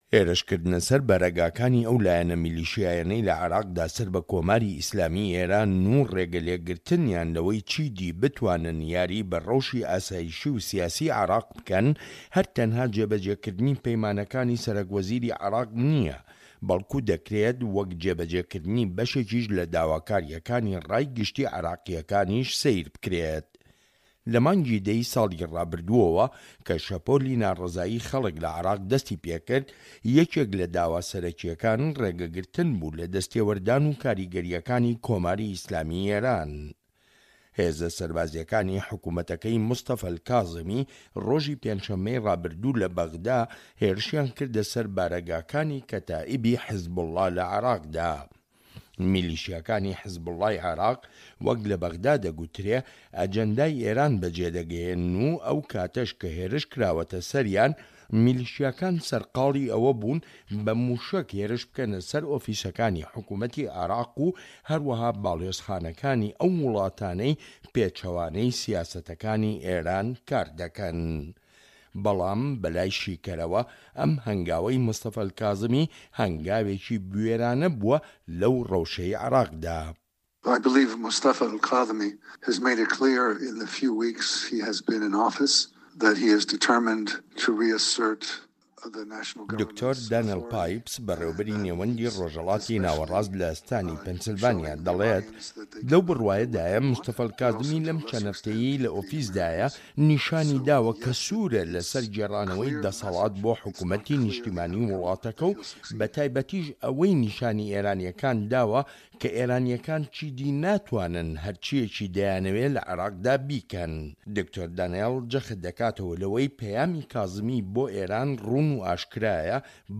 ڕاپۆرت لەسەر بنچینەی لێدوانەکانی دکتۆر دانێل پایپس